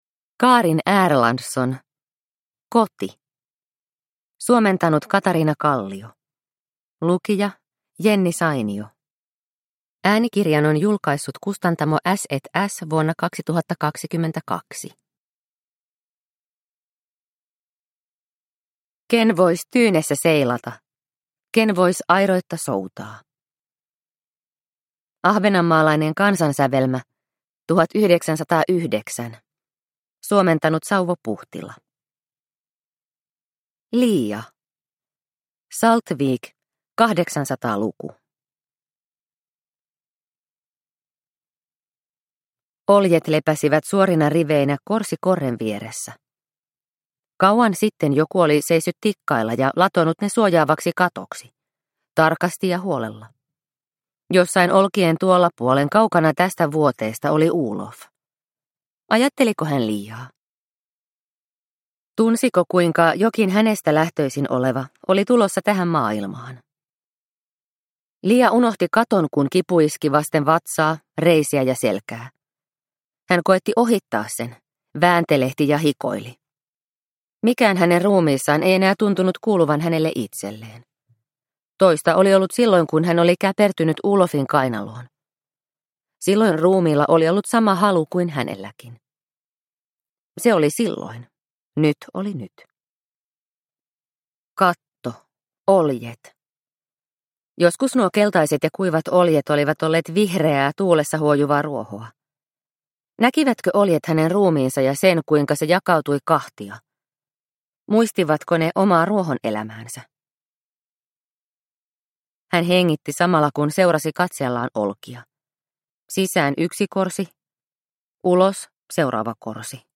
Koti – Ljudbok – Laddas ner